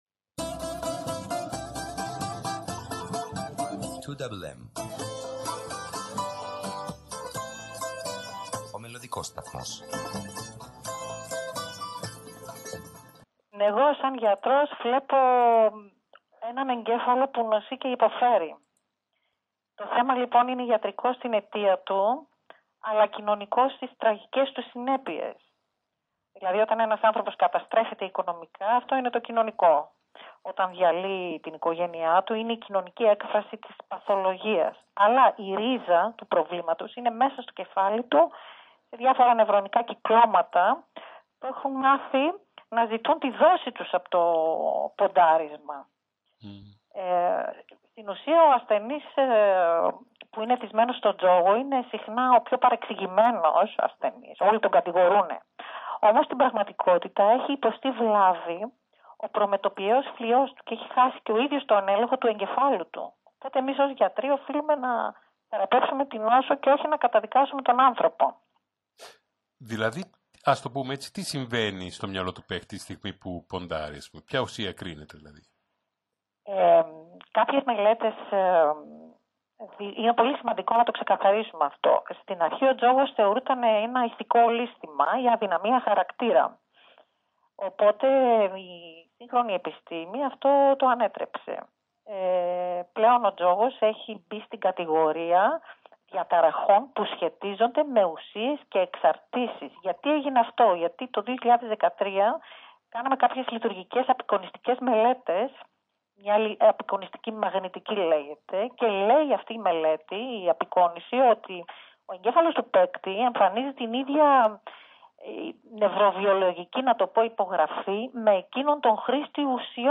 Έτσι ξεκίνησε η συνέντευξη